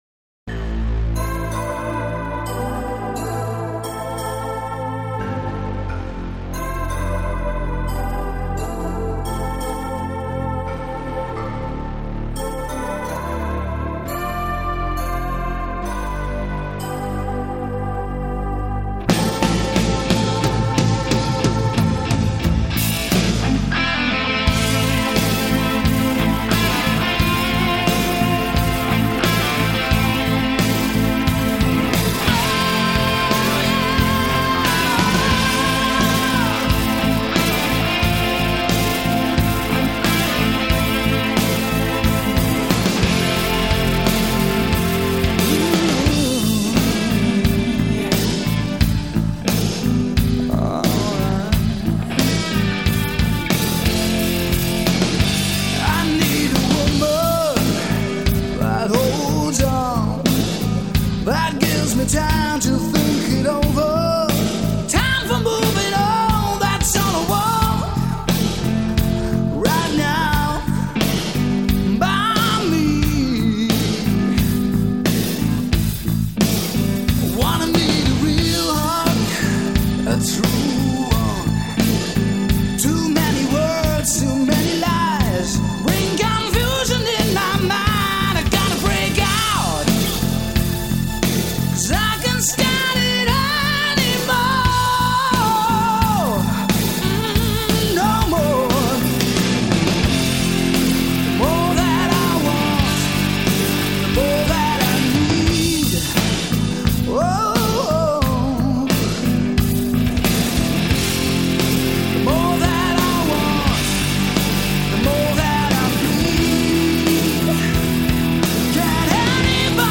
Жанр: Hard Rock